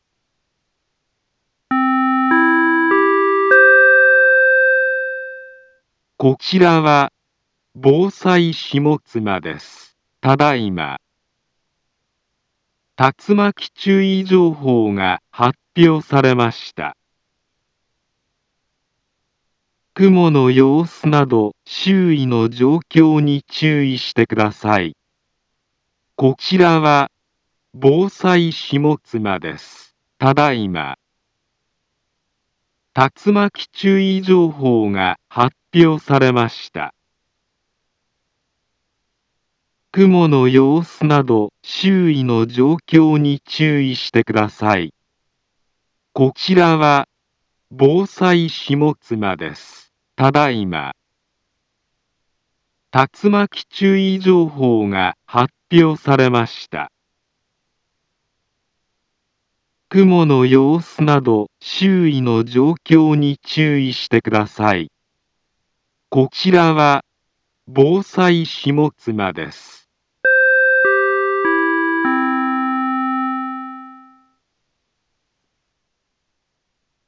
Back Home Ｊアラート情報 音声放送 再生 災害情報 カテゴリ：J-ALERT 登録日時：2021-05-01 22:55:12 インフォメーション：茨城県南部は、竜巻などの激しい突風が発生しやすい気象状況になっています。